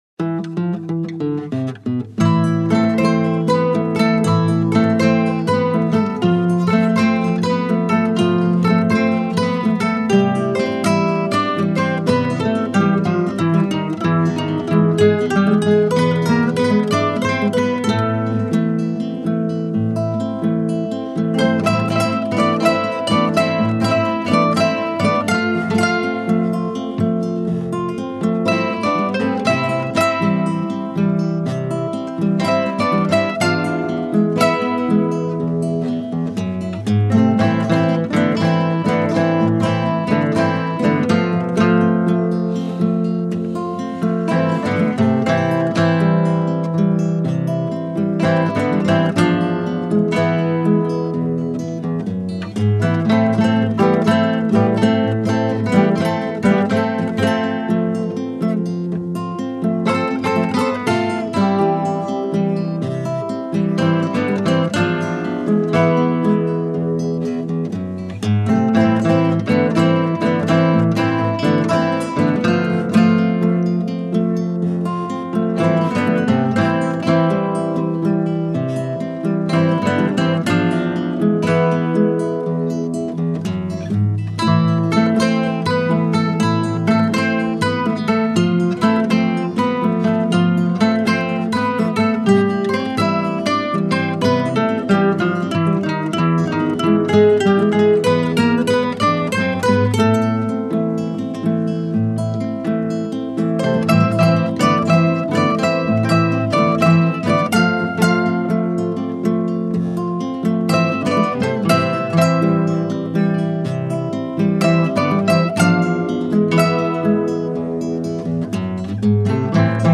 Desde la Gerencia de Formación Cultural, con el objetivo de potenciar las instancias formativas y en coordinación con la Usina Cultural de Parque del Plata, se grabaron productos musicales de los participantes de los talleres que se llevan a cabo en el departamento.
1._guitarra_milonga_santa_lucia.mp3